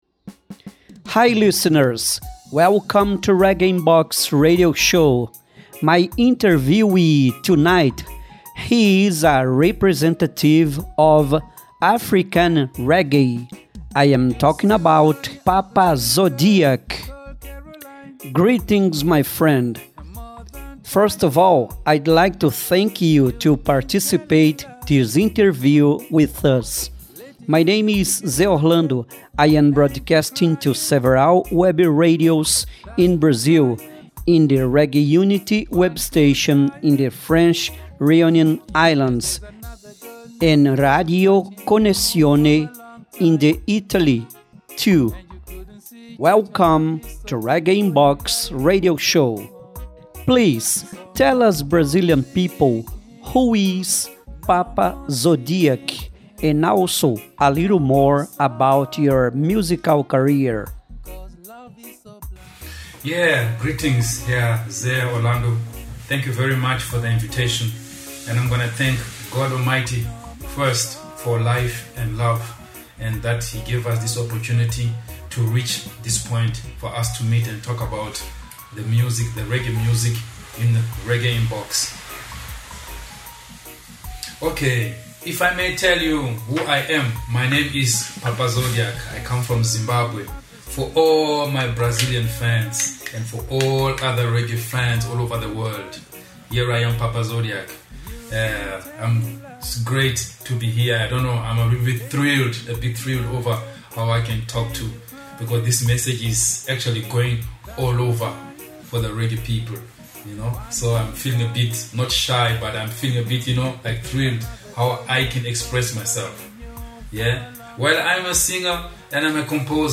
RIB Entrevista Cantor do Zimbabue